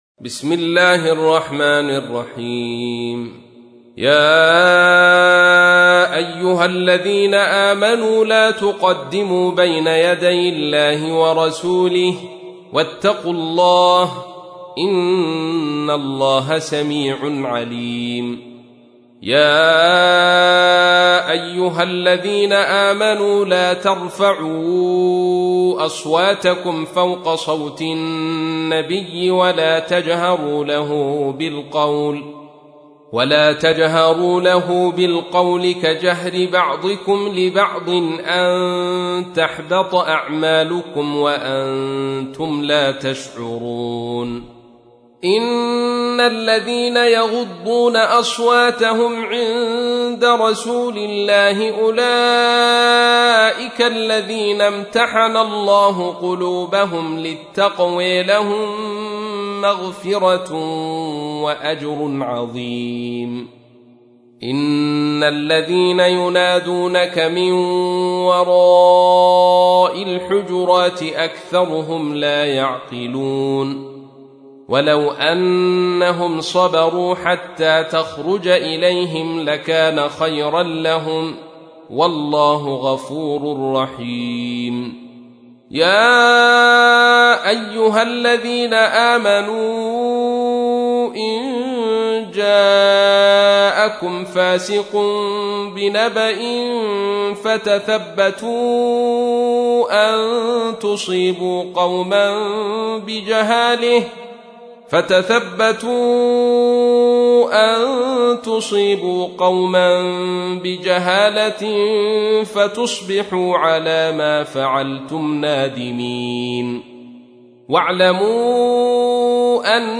تحميل : 49. سورة الحجرات / القارئ عبد الرشيد صوفي / القرآن الكريم / موقع يا حسين